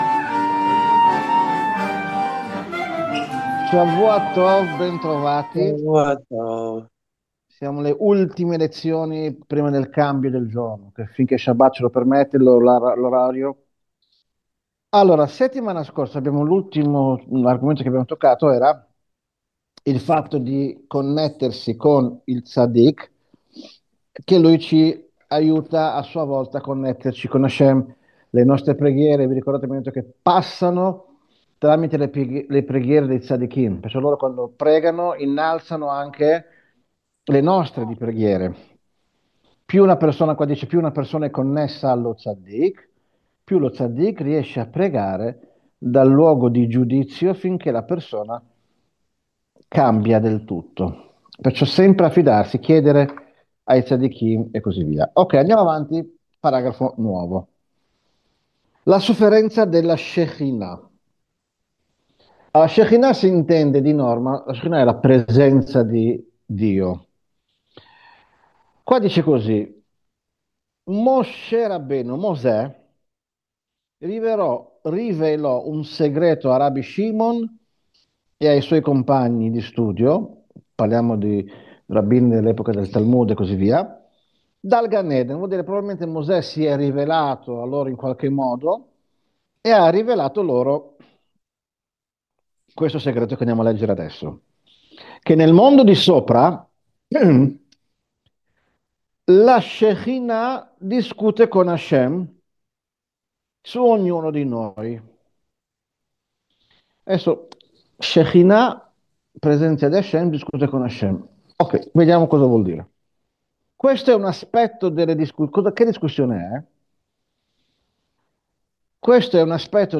Lezione del 14 marzo 2026